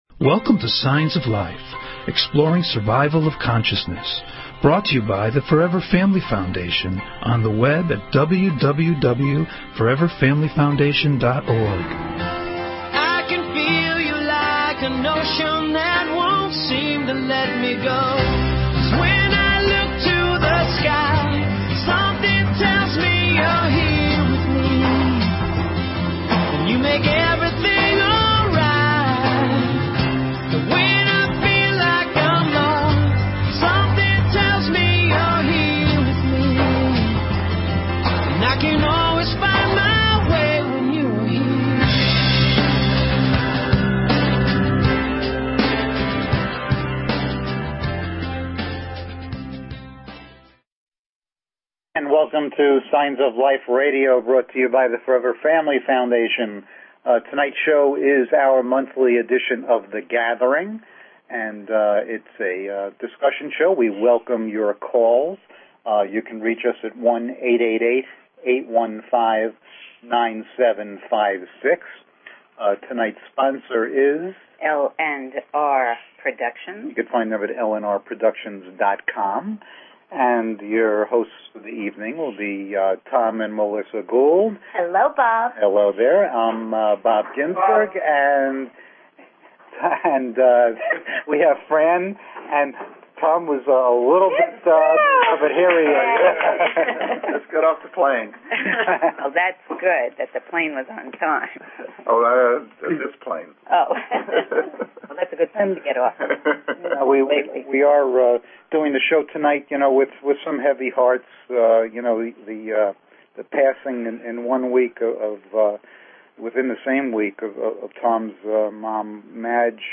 SHORT DESCRIPTION - The Gathering - Afterlife Discussion Show. Topic: Reincarnation - Do we all come back?
Call In or just listen to top Scientists, Mediums, and Researchers discuss their personal work in the field and answer your most perplexing questions.